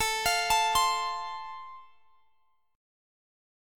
Listen to Am#5 strummed